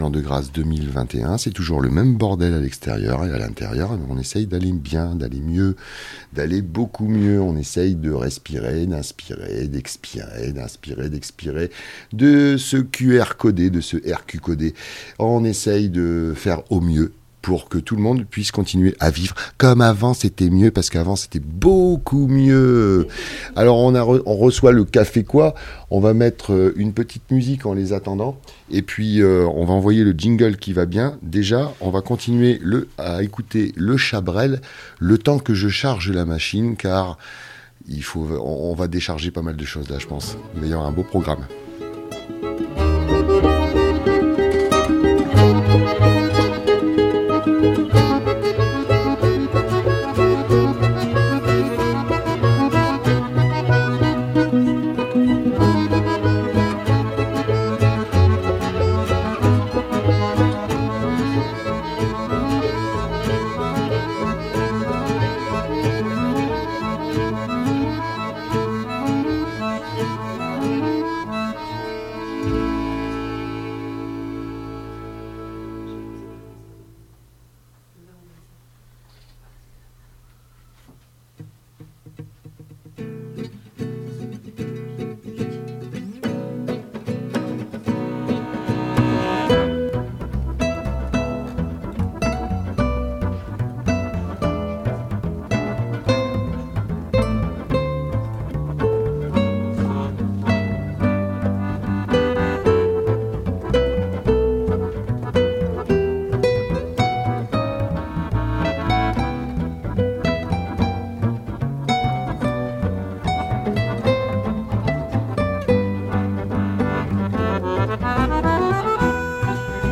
Le magazine du K'fé Quoi Forcalquier (36.93 Mo) Nous vous proposons de retrouver dans cette entrevue, quelques membres de la direction de l'équipe du K'fé Quoi pour vous présenter le programme des trois mois à venir.